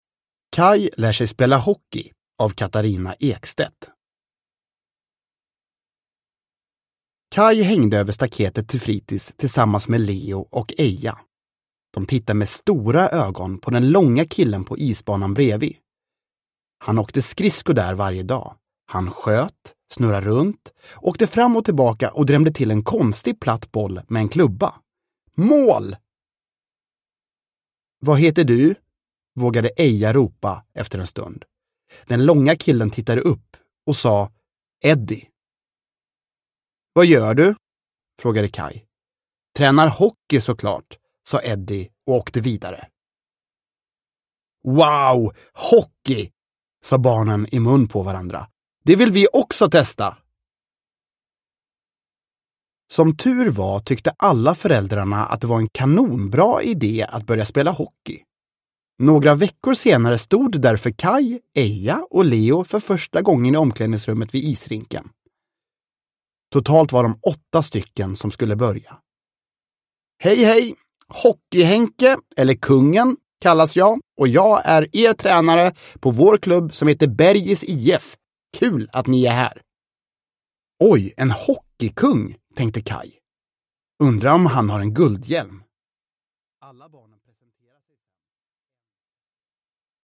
Kaj lär sig spela hockey – Ljudbok